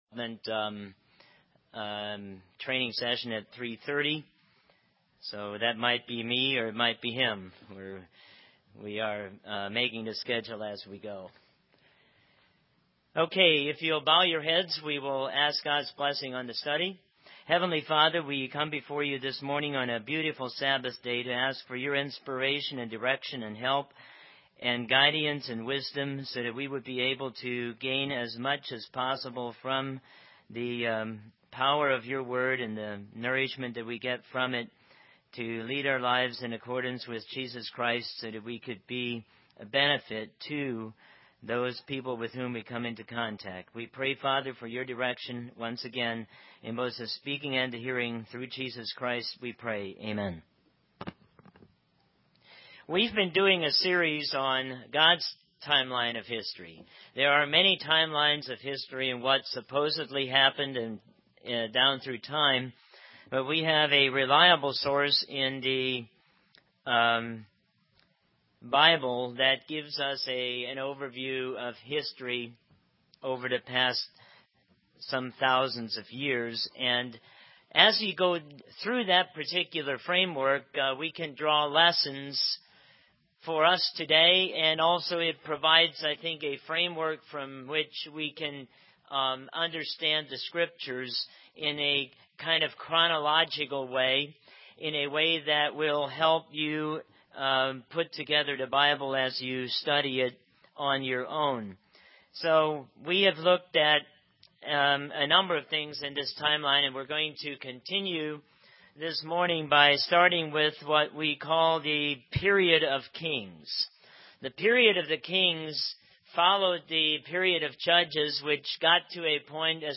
Given in North Canton, OH
Israel rejects God as King, UCG Sermon Studying the bible?